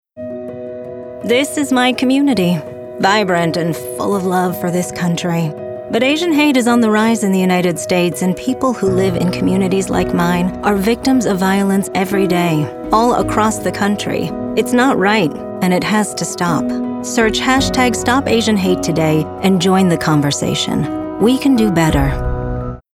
Political Voice Demos
Professional Female Political Voiceover
• Home Studio